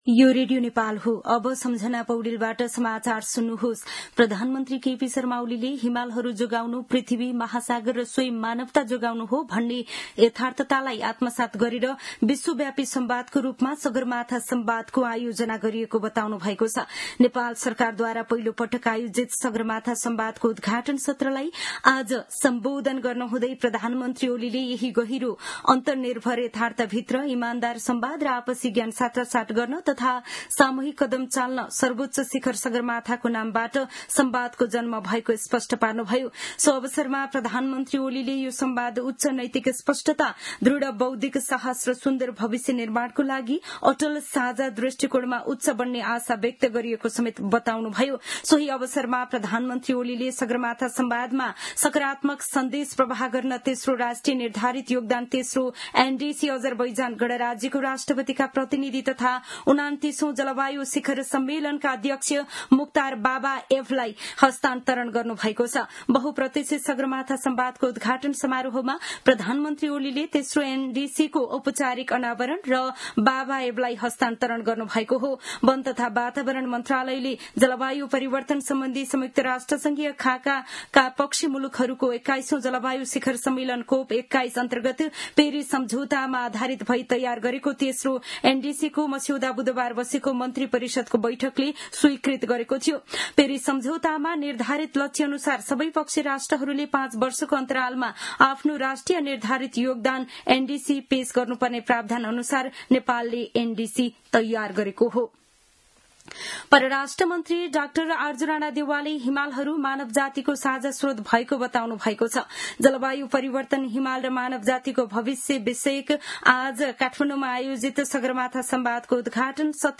दिउँसो १ बजेको नेपाली समाचार : २ जेठ , २०८२
1-pm-news-1-3.mp3